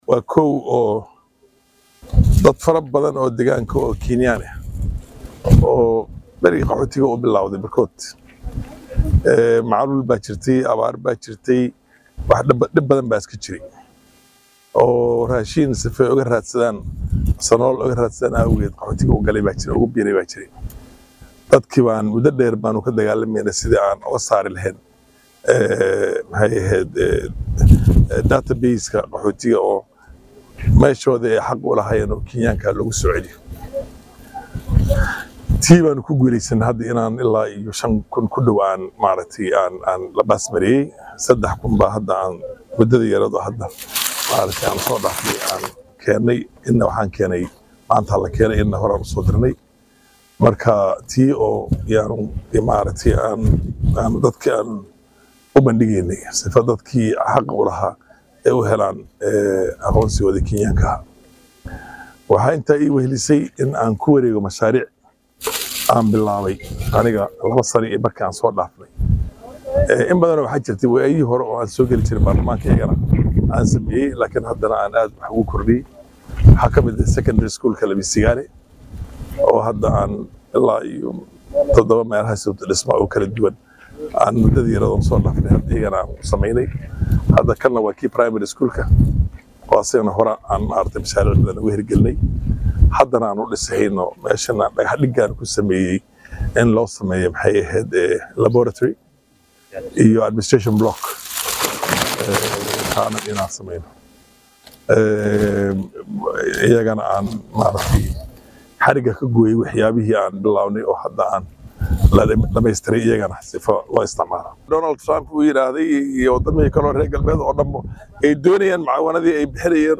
DHEGEYSO:Faarax macallin oo ka hadlay mashruucyada ay dowladda ka hirgelisay deegaanka Dhadhaab